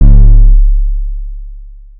Waka KICK Edited (5).wav